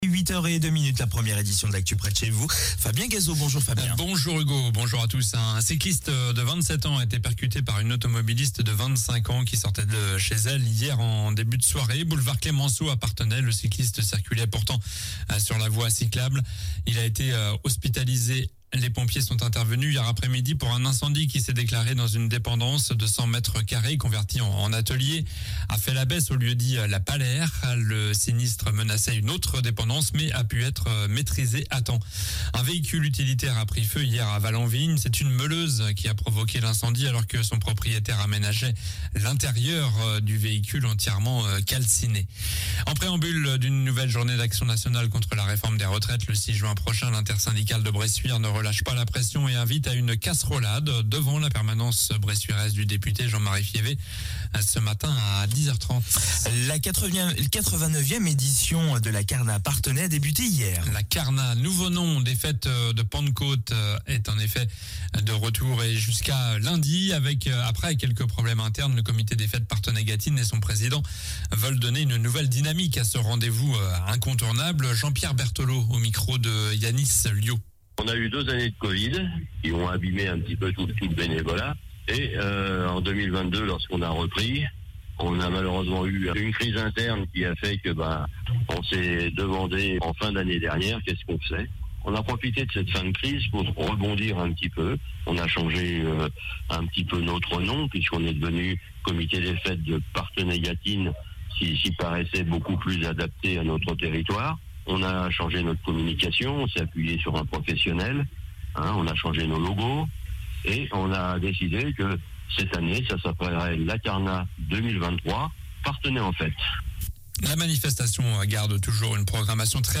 Journal du samedi 27 mai (matin)